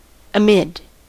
Ääntäminen
US : IPA : [əˈmɪd]